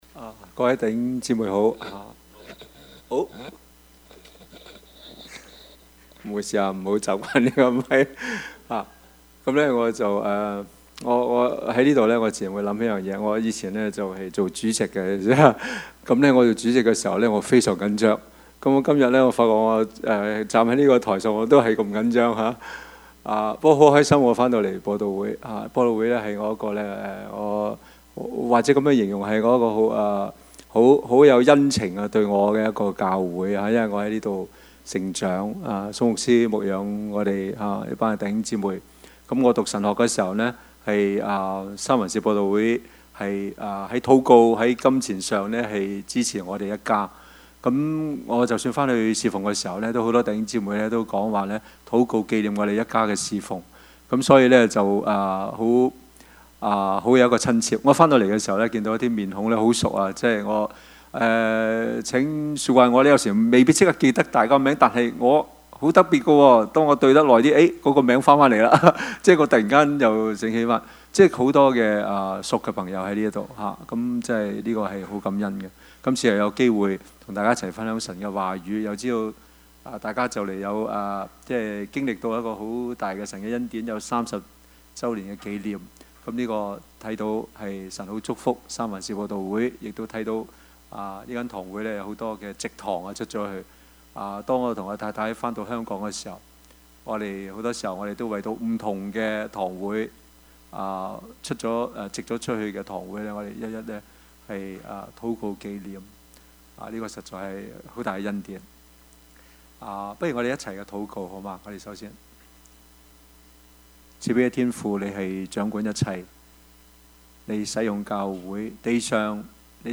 Service Type: 主日崇拜
Topics: 主日證道 « 走出方舟 萬世戰爭 »